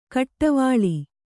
♪ kaṭṭavāḷi